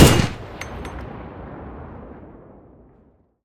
gun-turret-end-2.ogg